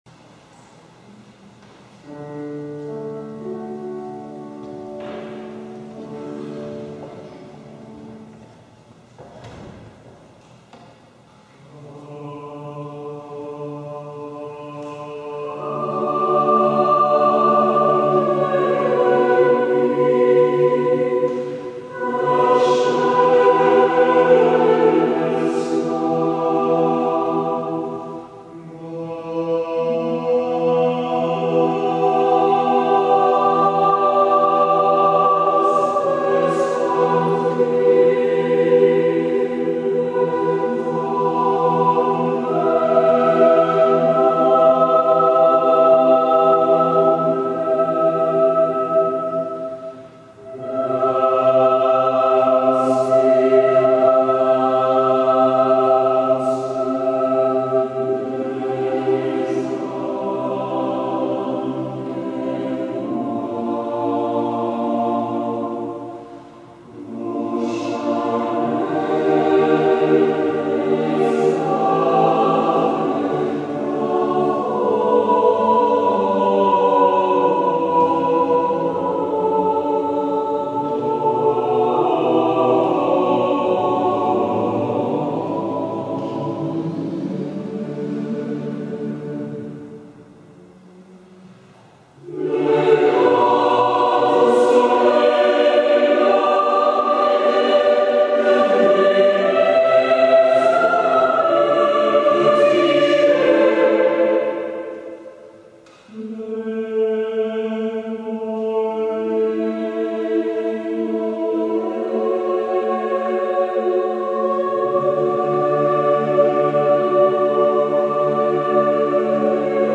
Music Category:      Choral